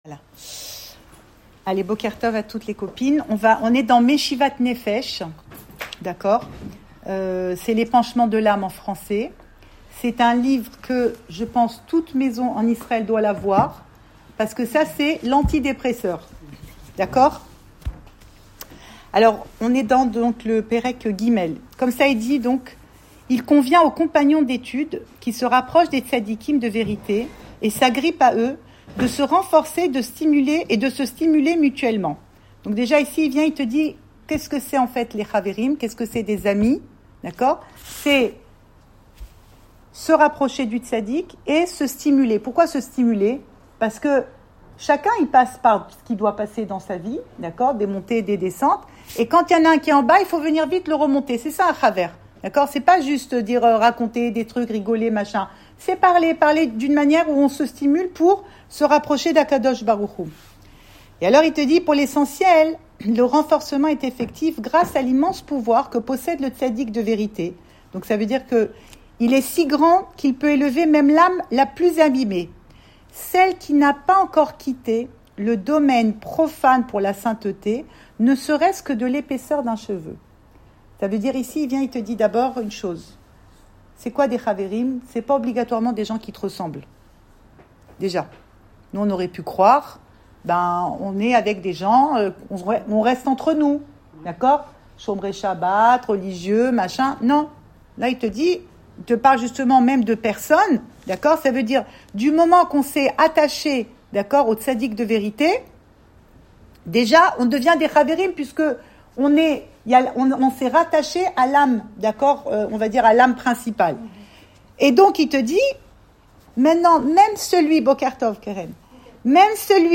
Cours audio Le coin des femmes Le fil de l'info Pensée Breslev - 5 février 2025 6 février 2025 Ménopause : encore une étape dans la vie d’une femme. Enregistré à Tel Aviv